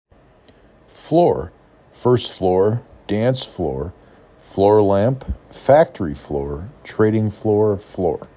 5 Letters, 1 Syllable
f l or